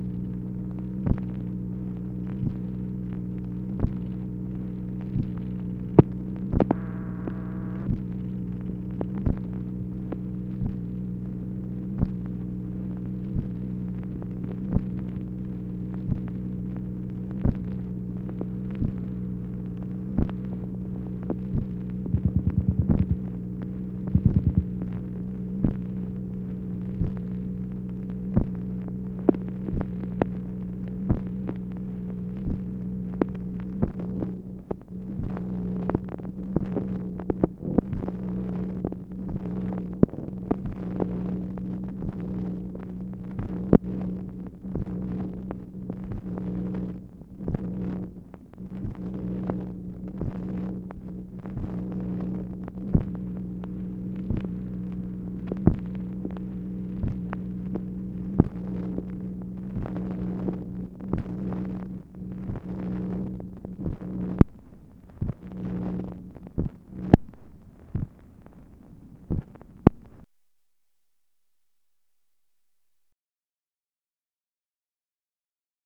MACHINE NOISE, January 30, 1964
Secret White House Tapes | Lyndon B. Johnson Presidency